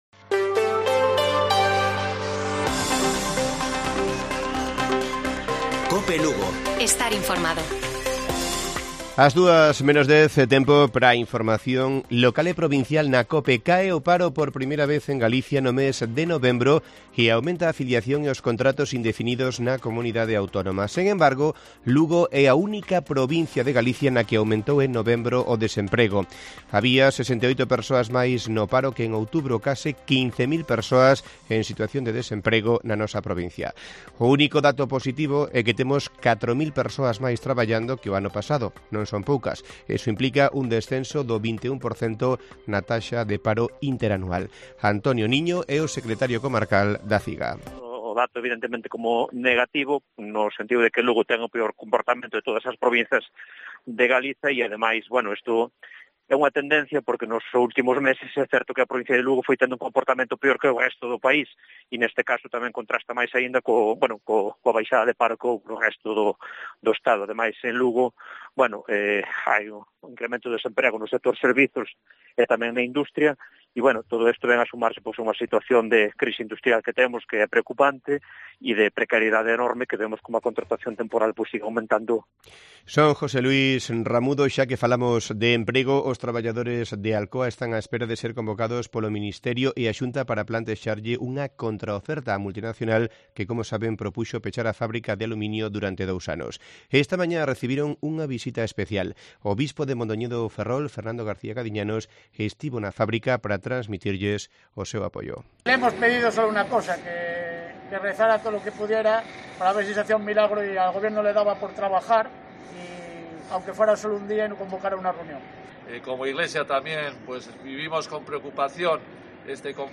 Informativo Mediodía de Cope Lugo. 02 de diciembre. 13:50 horas